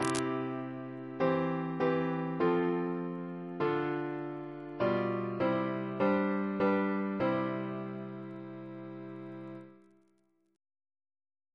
Single chant in C Composer: Richard Goodson (1655-1718), Organist of New College and Christ Church, Oxford Reference psalters: ACB: 22; ACP: 130; CWP: 90; H1940: 609; H1982: S229; OCB: 72; PP/SNCB: 1; RSCM: 177